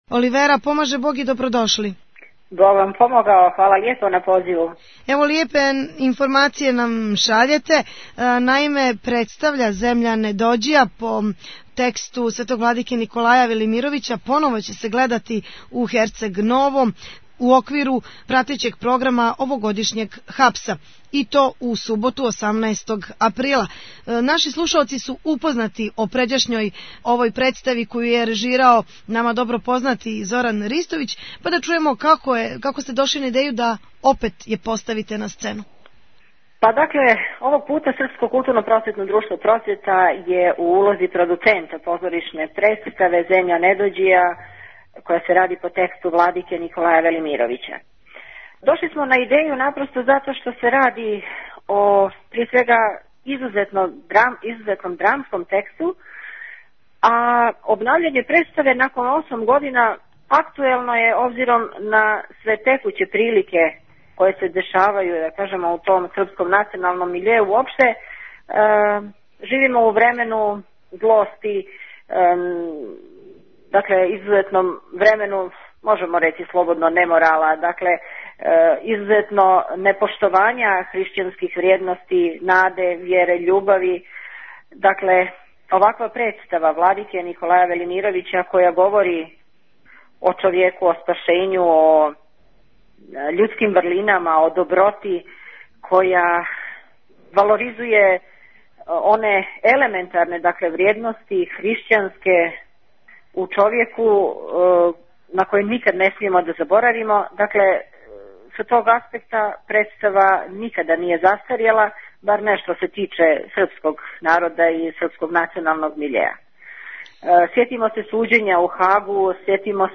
Актуелни разговори